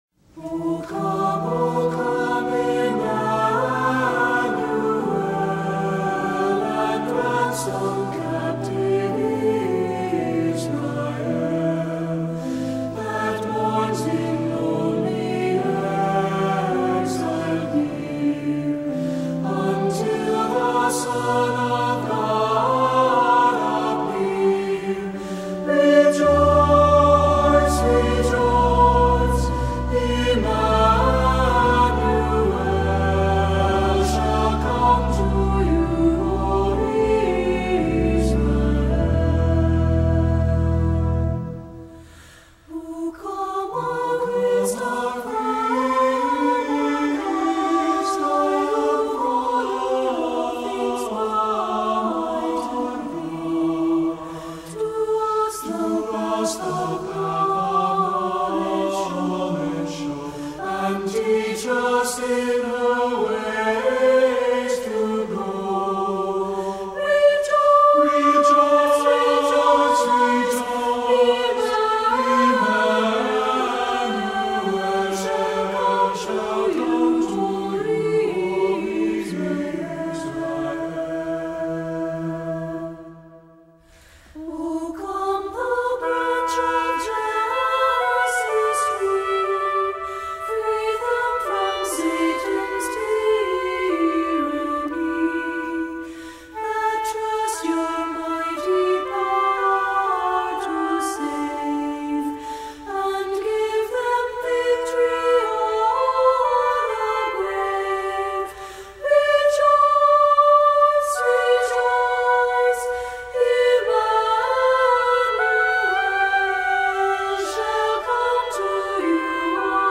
This soul-stirring and evocative hymn contemplate the people of Israel’s anticipation of their coming savior.